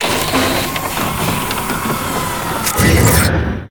repair.ogg